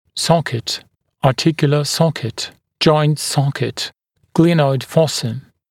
[‘sɔkɪt] [ɑː’tɪkjulə ‘sɔkɪt] [ʤɔɪnt ‘sɔkɪt] [‘gliːnɔɪd ‘fɔsə] [‘glenɔɪd ‘fɔsə][‘сокит] [а:’тикйулэ ‘сокит] [джойнт ‘сокит] [‘гли:нойд ‘фосэ] [‘глэнойд ‘фосэ]суставная ямка